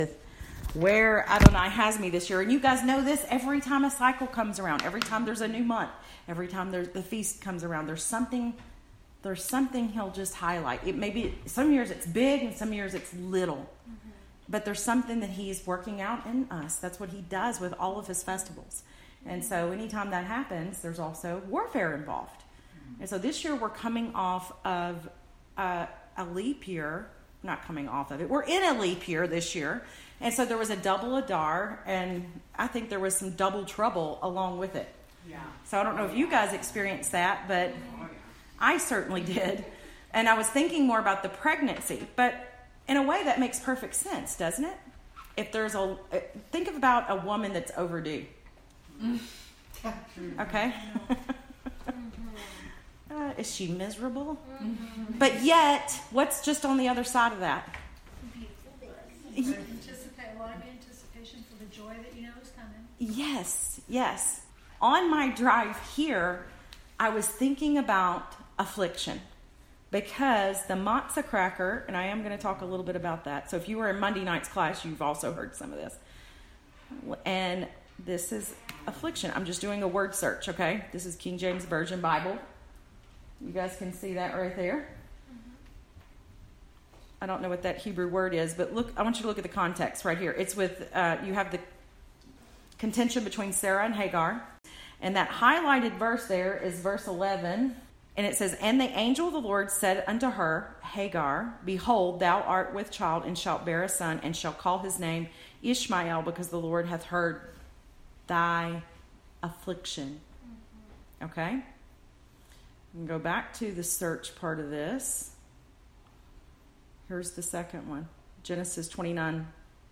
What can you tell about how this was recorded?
This article is based on a quick message at our local new moon gathering. new-moon-meeting-nisan-2019.mp3